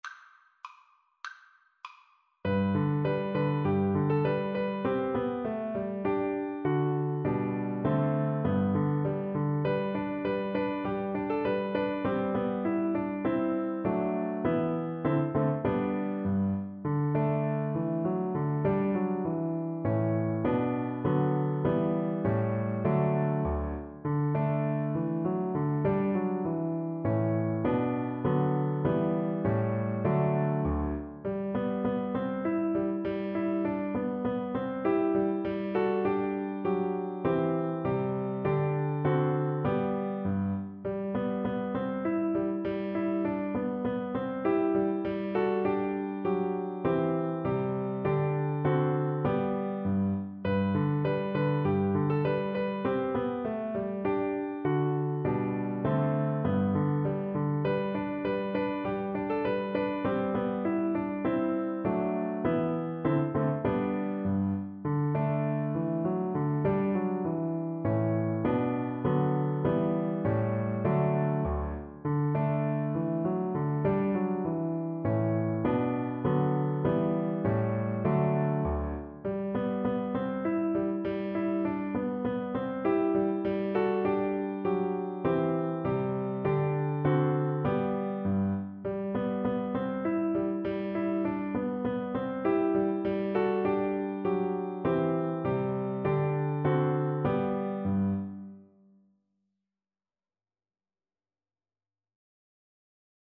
Free Sheet music for Soprano (Descant) Recorder
2/2 (View more 2/2 Music)
~ = 200 A1